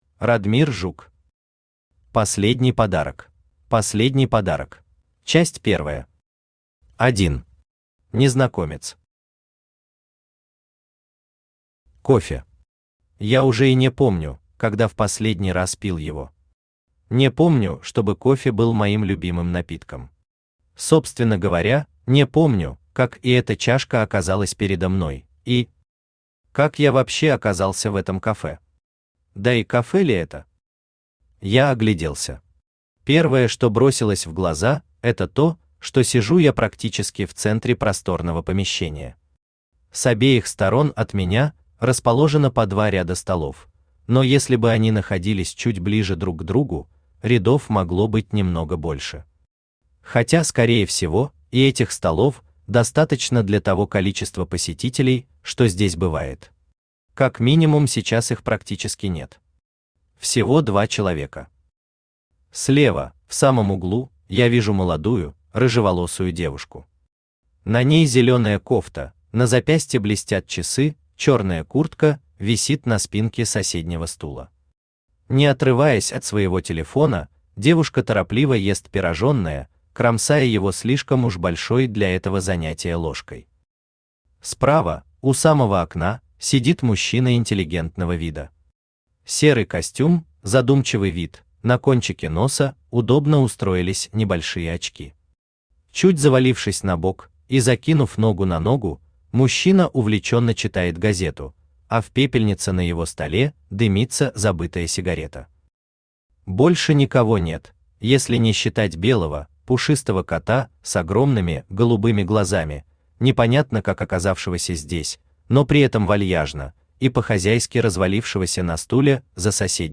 Aудиокнига Последний подарок Автор Радмир Рамильевич Жук Читает аудиокнигу Авточтец ЛитРес.